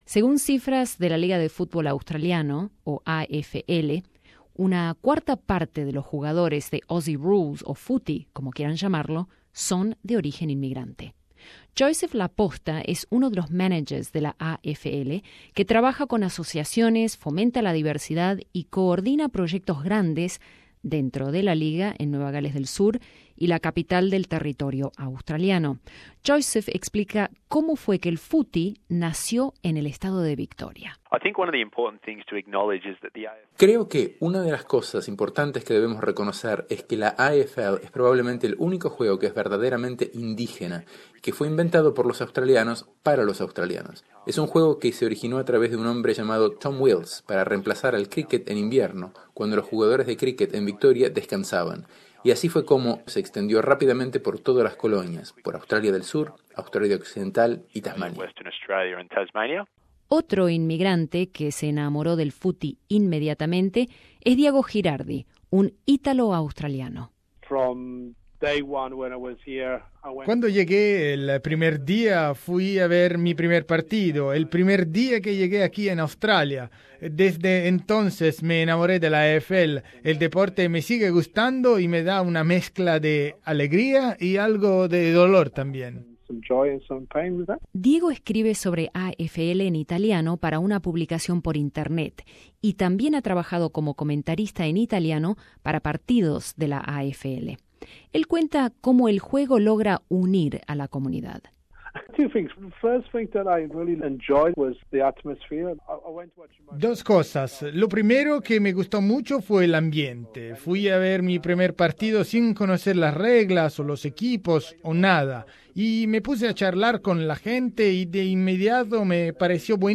Escucha nuestro informe sobre por qué el Footy apasiona a tantas personas que vinieron a Australia de todas partes del mundo.